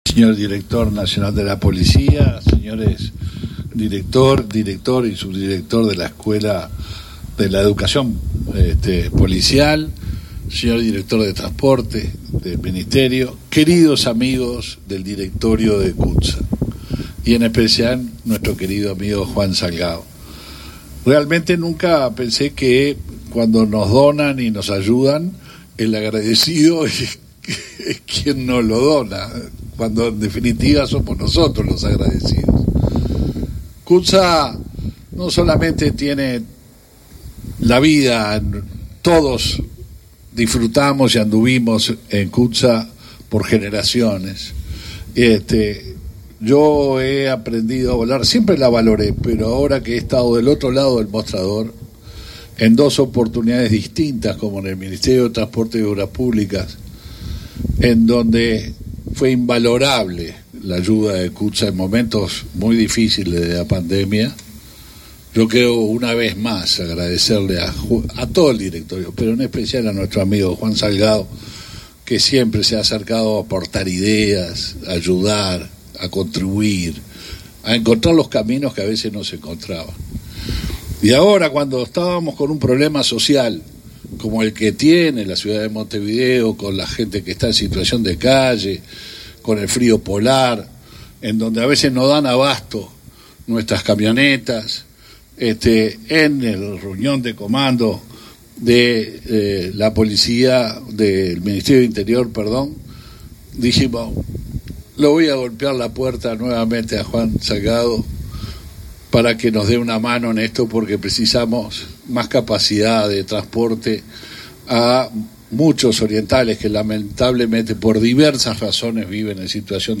Palabras del ministro del Interior, Luis Alberto Heber, con motivo de la firma de un comodato con la Compañía Uruguaya de Transportes Colectivos S.A.